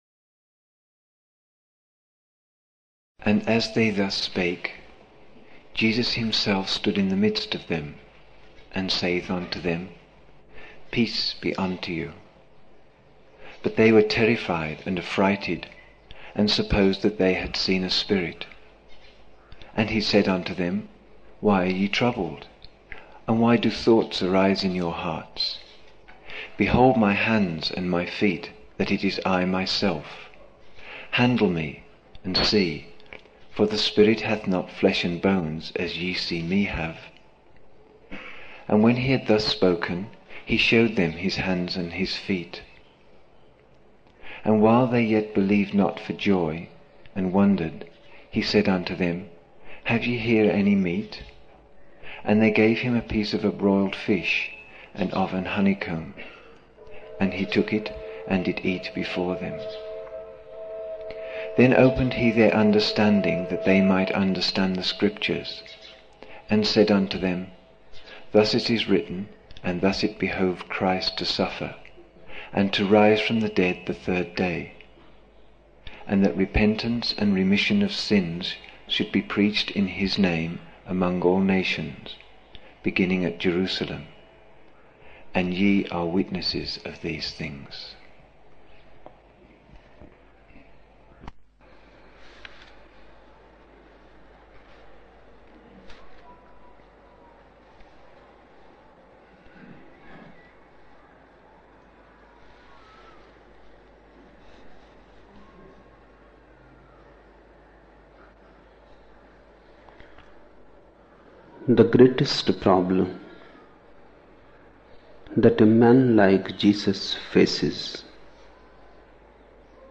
9 November 1975 morning in Buddha Hall, Poona, India